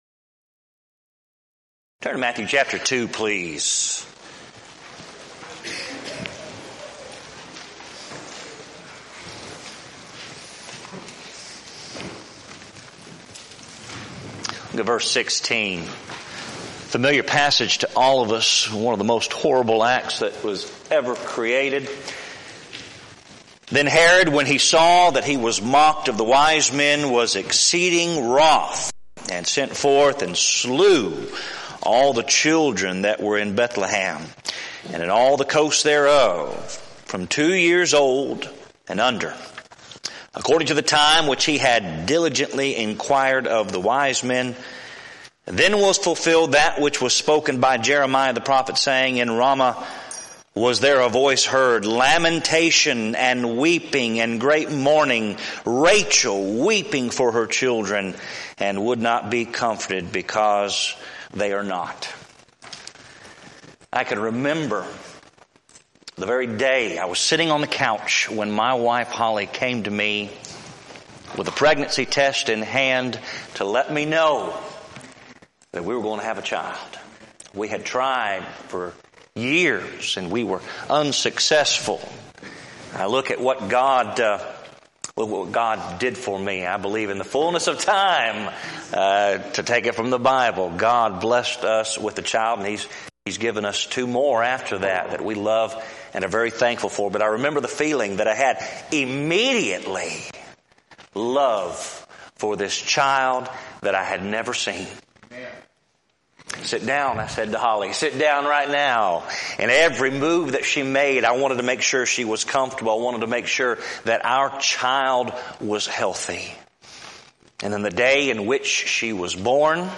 Event: 6th Annual BCS Men's Development Conference
lecture